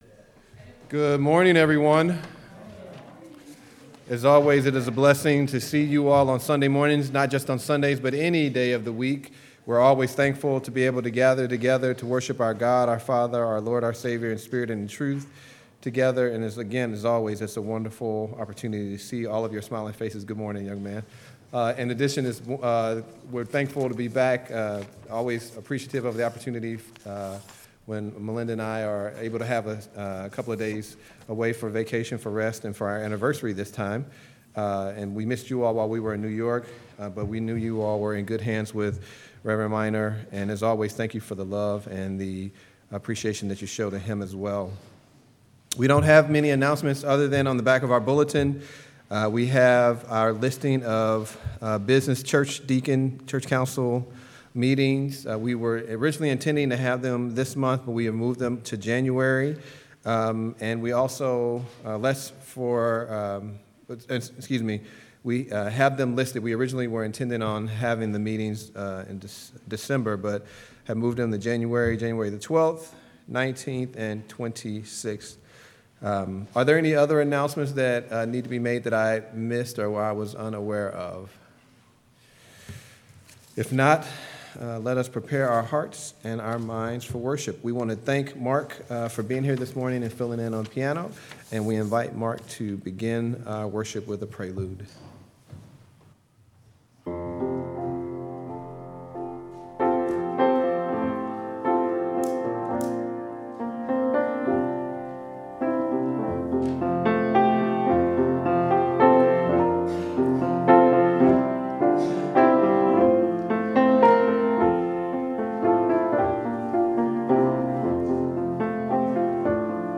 Please, click the button below to hear this week's service.